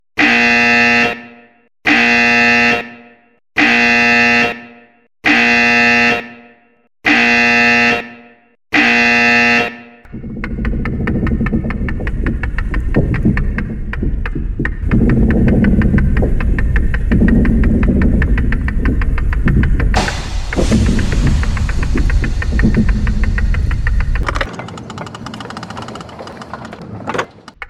bigdoorclose.mp3